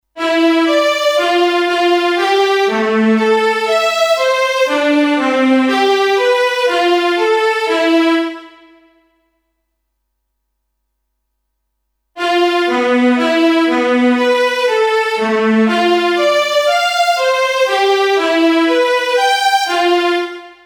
Fig. 4.1. Due esempi di “melodie casuali” generate dal computer nella
tonalità di Do .